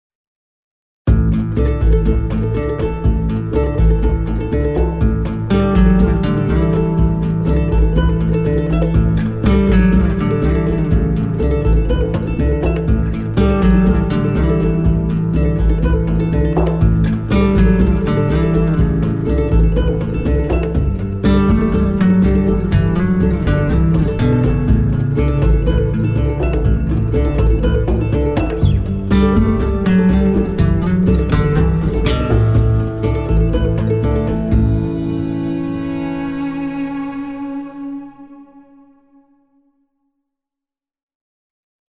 Largo [40-50] amour - accordeon - - -